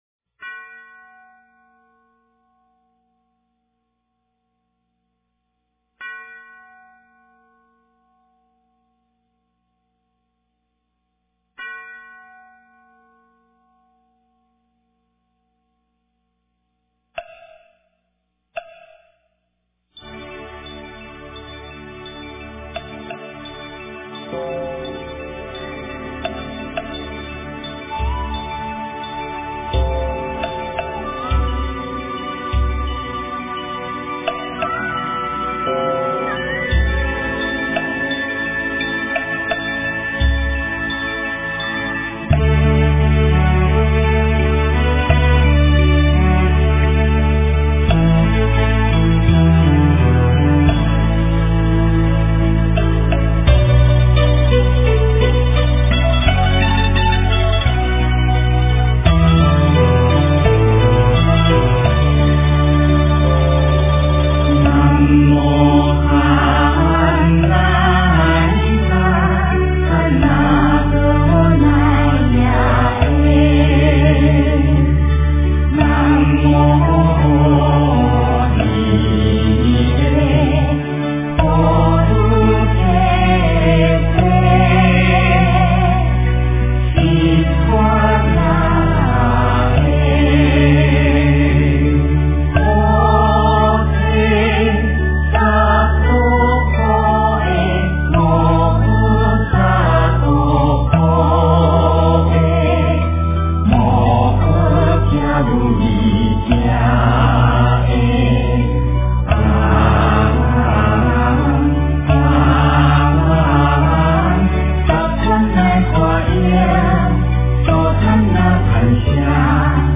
大悲咒-闽南语 诵经 大悲咒-闽南语--新韵传音 点我： 标签: 佛音 诵经 佛教音乐 返回列表 上一篇： 般若波罗蜜多心经 下一篇： 大悲咒 相关文章 念三宝--圆满自在组 念三宝--圆满自在组...